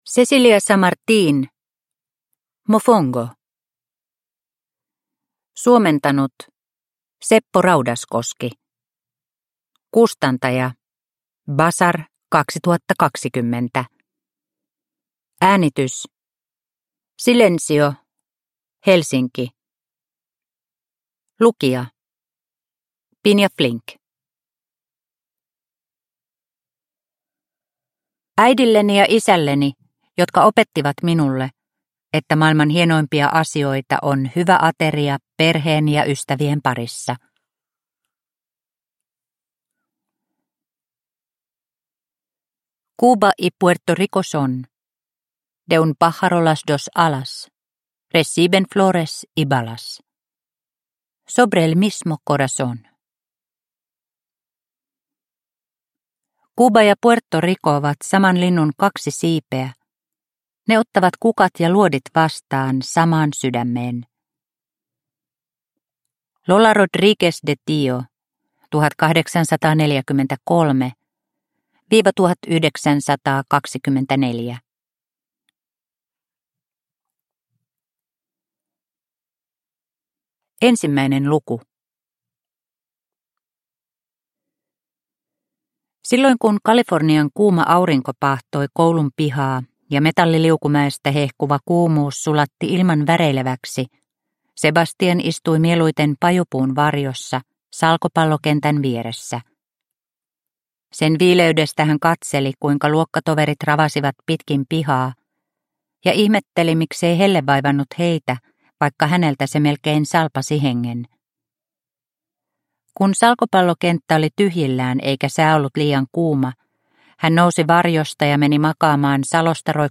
Mofongo – Ljudbok – Laddas ner